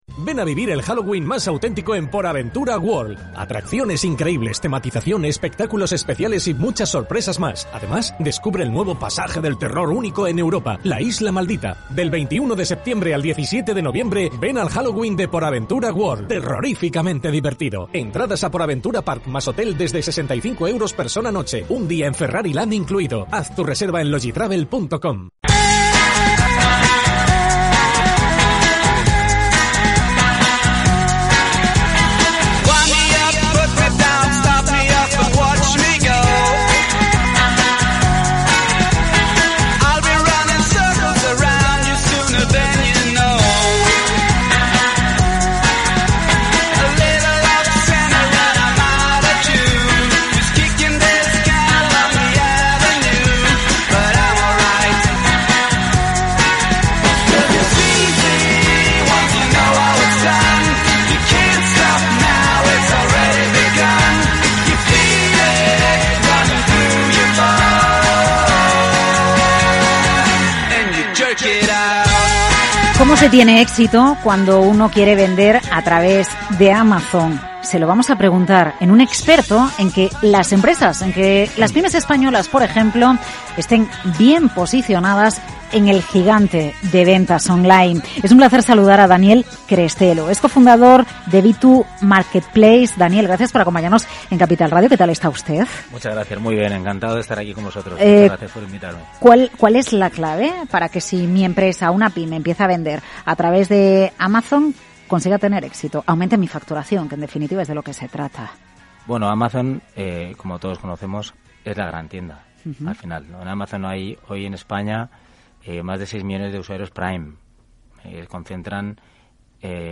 El posicionamiento en Amazon y la nueva adquisición de B2Marketplace por parte de Antevenio han sido los temas tratados en esta entrevista en Capital Radio